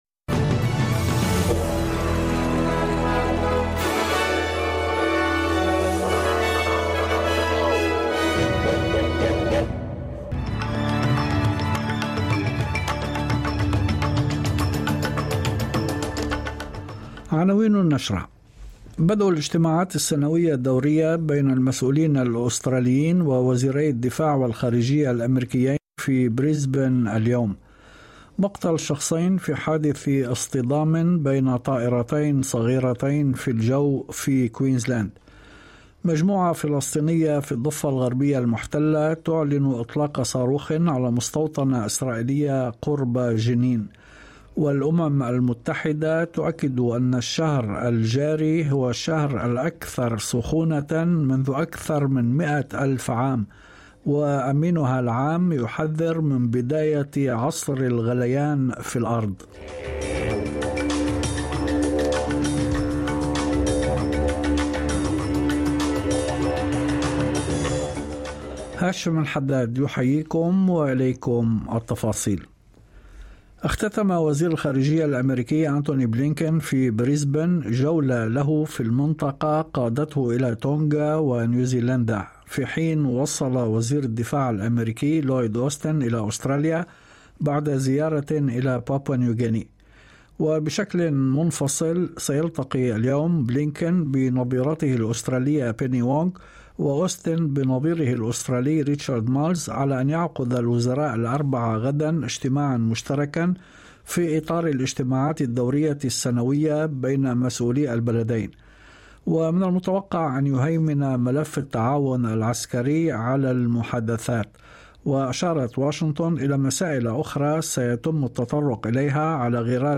نشرة أخبار المساء 28/07/2023